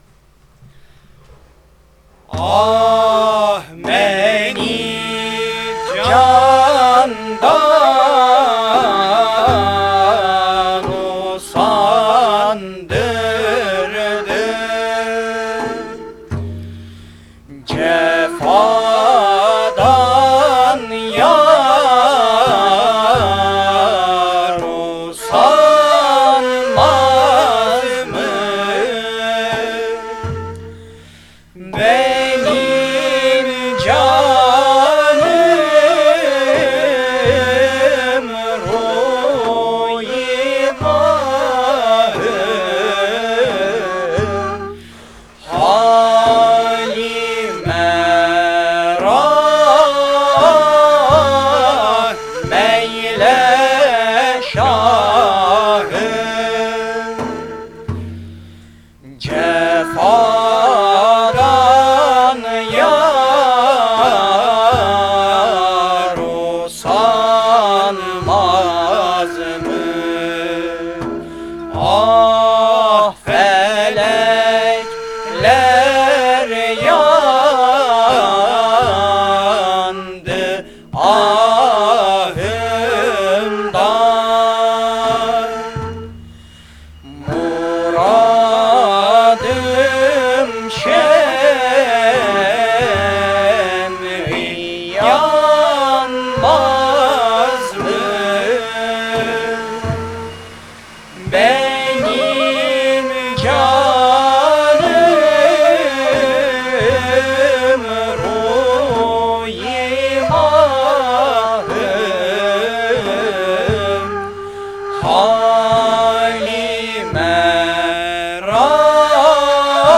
Eser: Beni Candan Usandırdı Cefâdan Yar Usanmaz mı Bestekâr: Ertuğrul İnanç Güfte Sâhibi: Fuzûlî Makam: Isfahan Form: Ağır Semai Usûl: Aksak Semai...